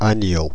• ÄäntäminenFrance:
• IPA: [ɛ̃.n‿a.ɲo]